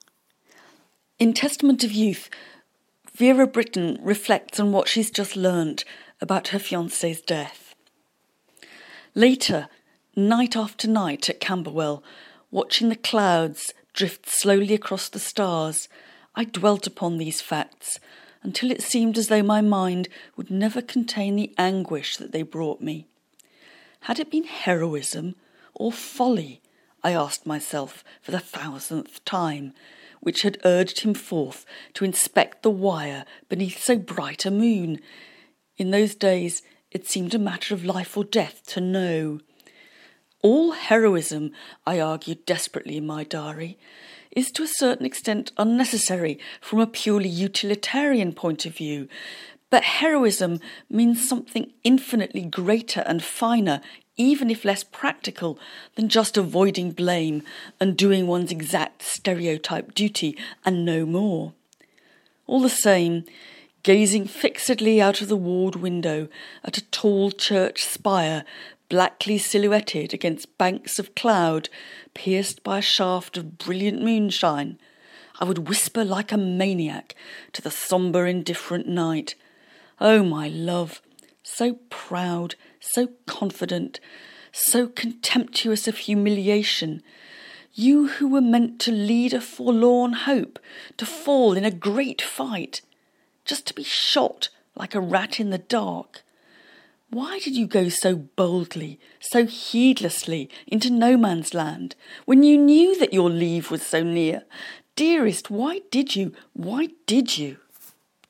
Read by Mary Beard